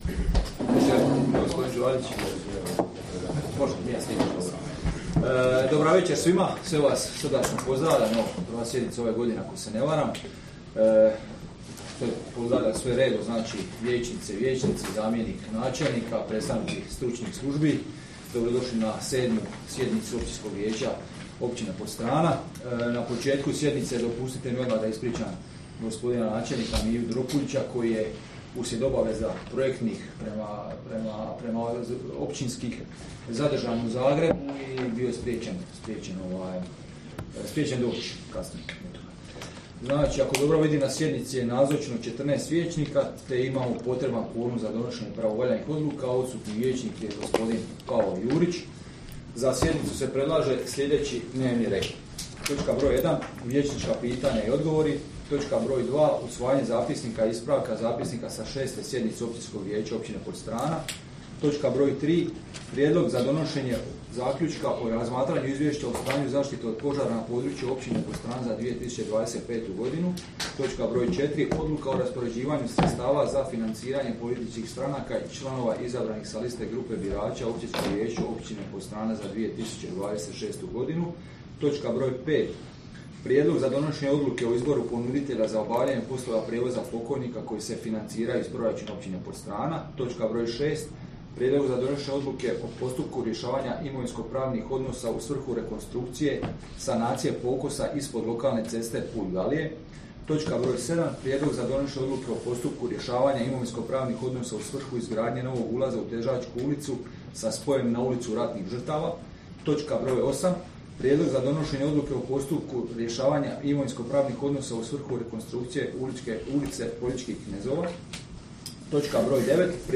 Sjednica će se održati dana 11. ožujka (srijeda) 2026. godine u 19,00 sati u Vijećnici Općine Podstrana.